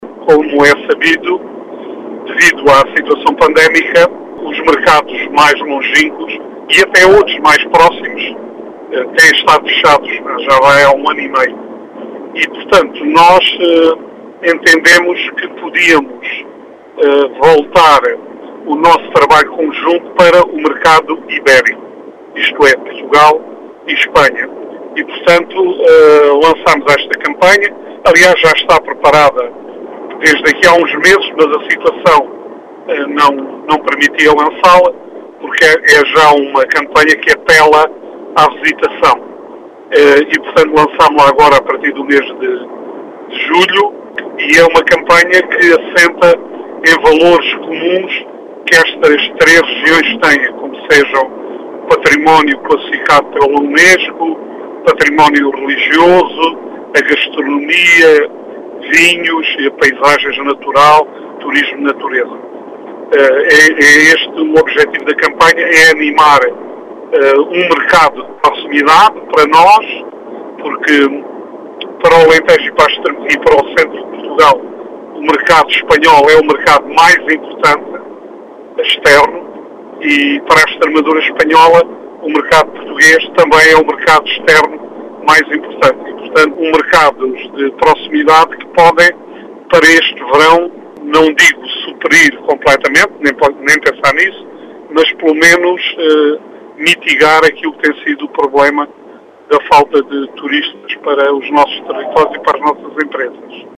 As explicações foram deixadas na Rádio Vidigueira, por Vitor Silva, presidente da Entidade Regional de Turismo do Alentejo que espera que esta campanha possa ajudar neste verão a “mitigar a falta de turistas no território”.